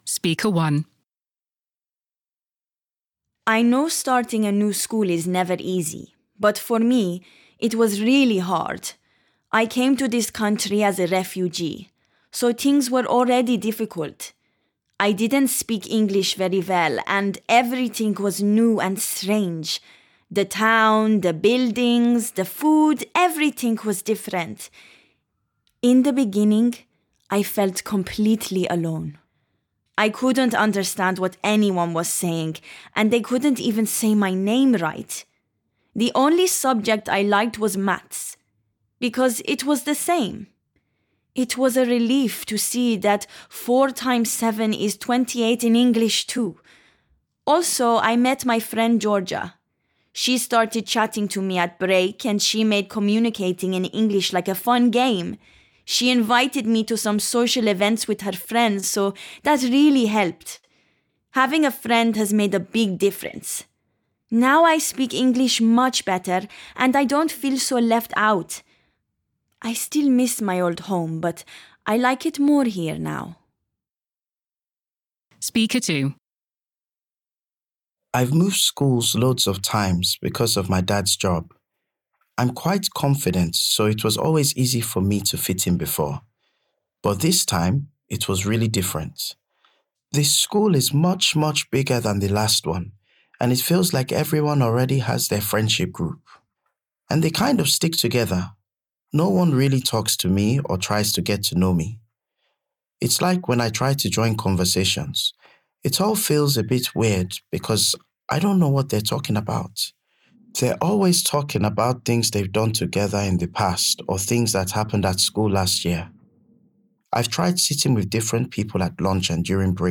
• Tell learners that they will listen to the teenagers again, one at a time, and answer the questions by choosing the correct option for each.
B1_monologue_school_v1.mp3